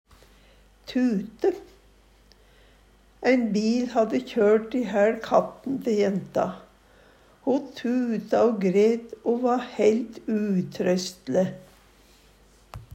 tute - Numedalsmål (en-US)